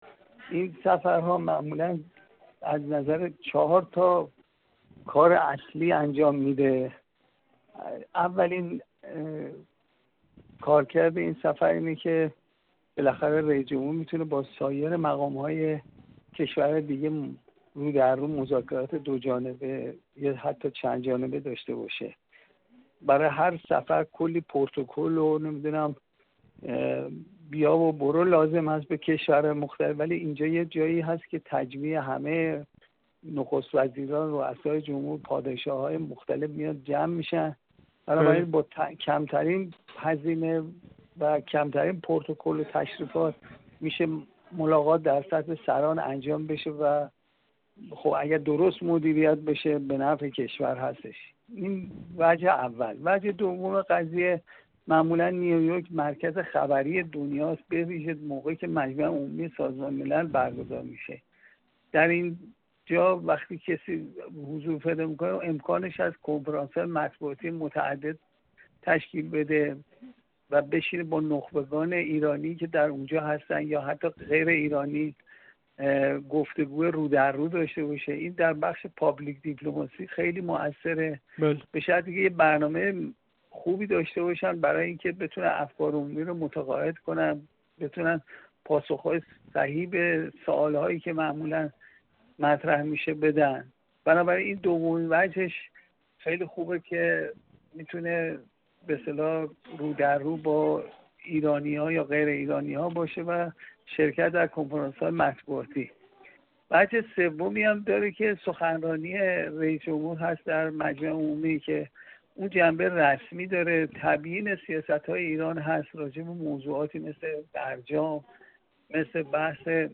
گفت‌وگو